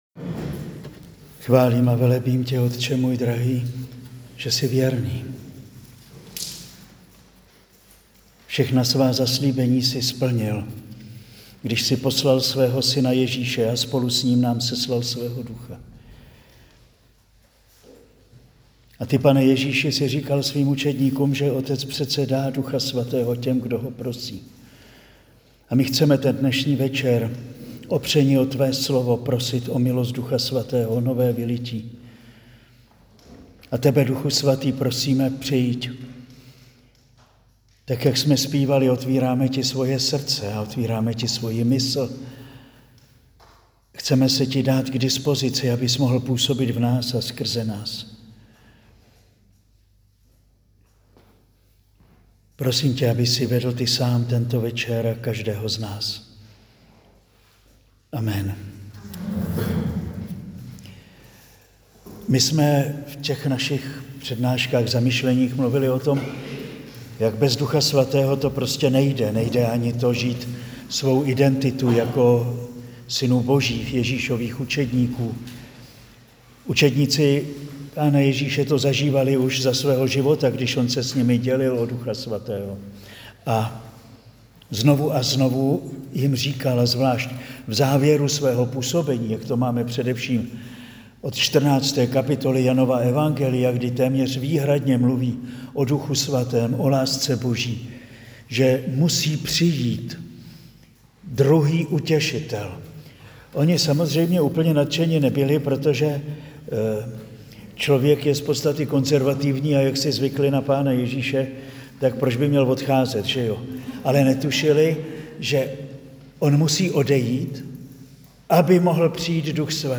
Přednáška zazněla na kurzu učednictví v květnu 2025